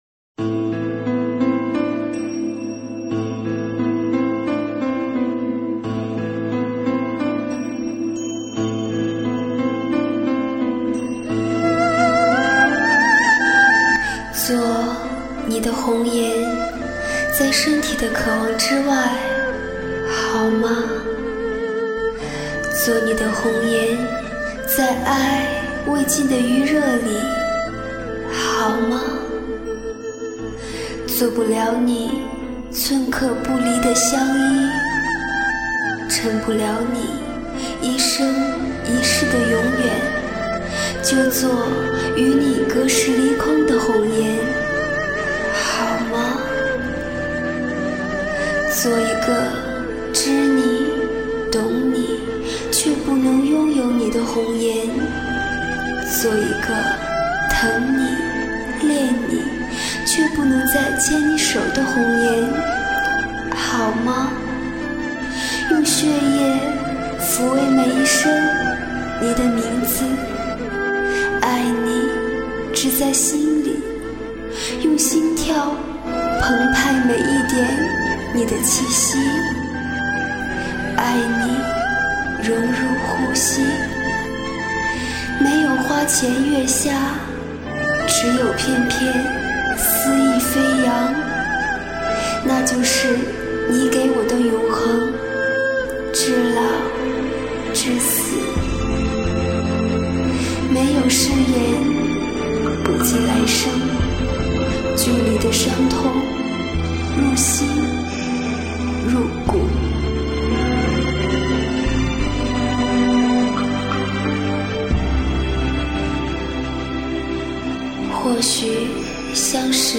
音乐，文字，图片。